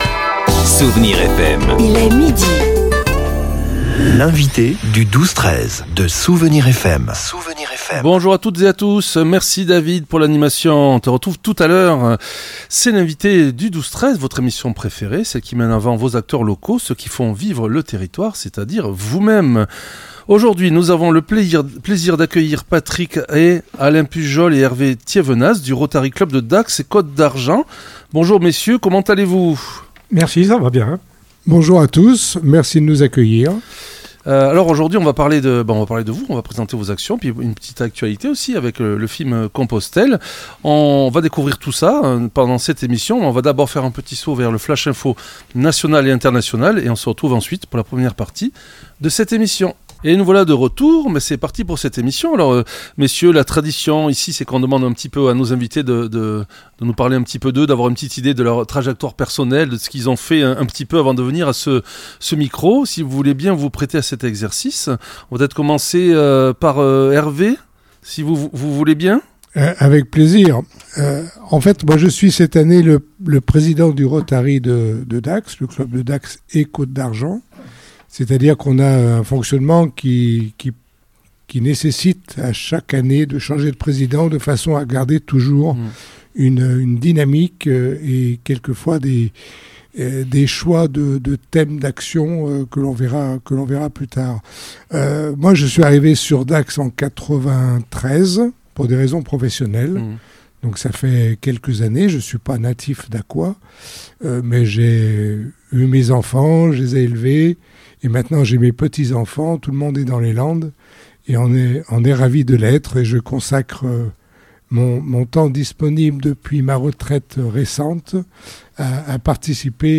L'entretien s'est focalisé sur l'opération phare "Espoir en tête", qui a déjà permis de verser près de 17 millions d'euros à la recherche fondamentale sur le cerveau en France.